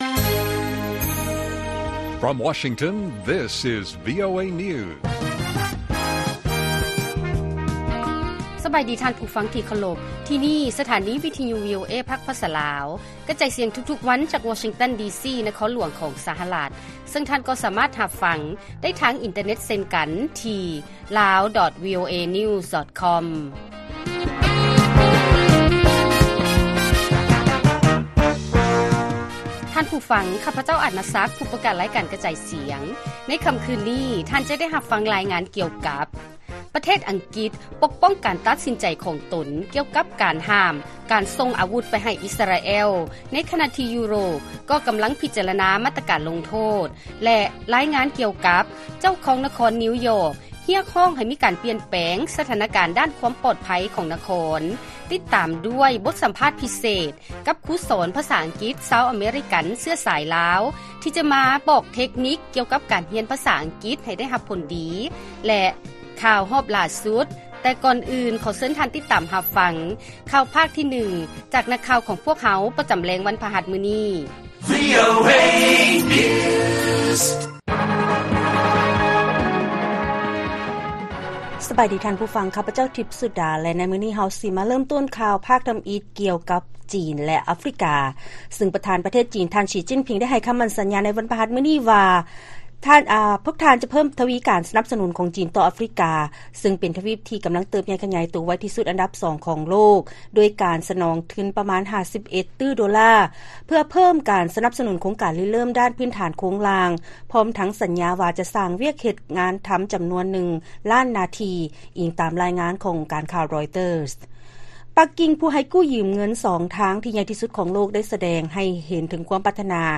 ລາຍການກະຈາຍສຽງຂອງວີໂອເອລາວ: ຈີນ ສະໜອງທຶນໃໝ່ໃຫ້ແກ່ ອາຟຣິກາ 51 ຕື້ໂດລາ ໂດຍສັນຍາວ່າຈະມີວຽກເຮັດງານທໍາ 1 ລ້ານຕໍາແໜ່ງ